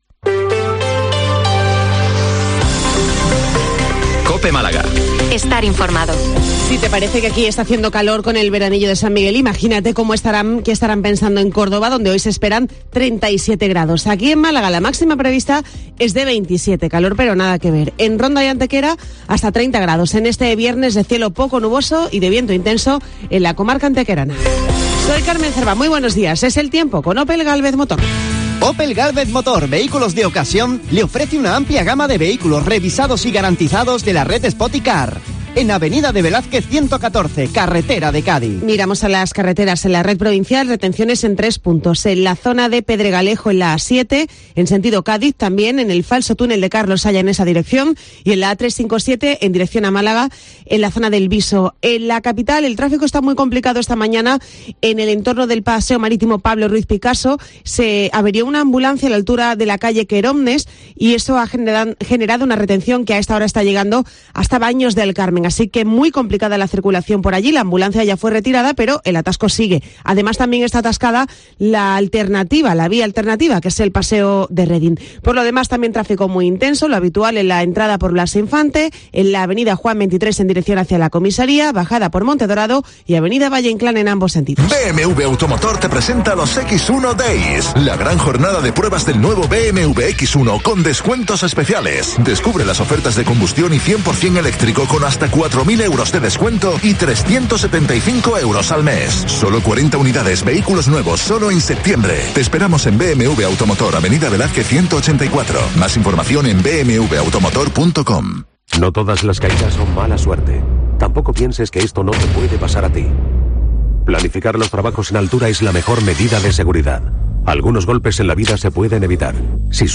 Informativo 08:24 Málaga - 290923